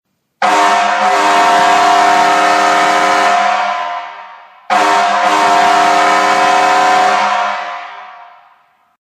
Set of high E-Tone & low E-Tone, air horns from US fire engines, chrome plated, 62cm & 46cm, false piercing tone
hadley-fire-engine-e-tone-set.mp3